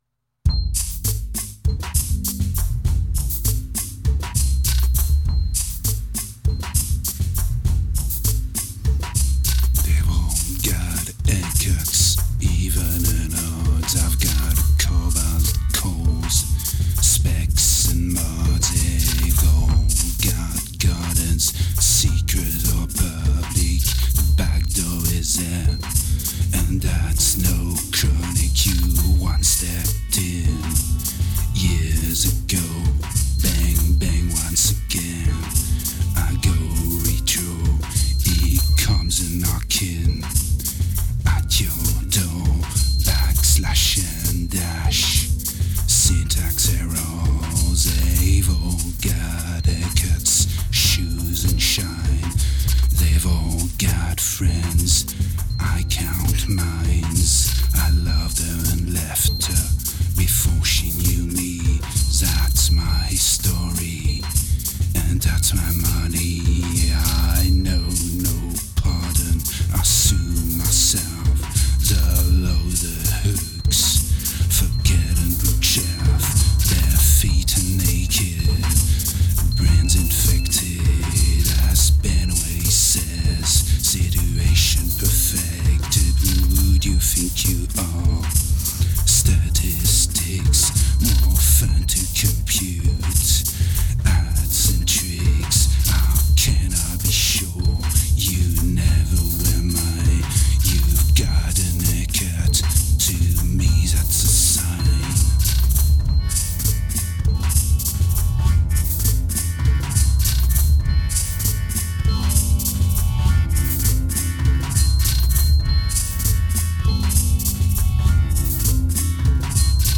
a dark album, fast and poorly recorded during late 2007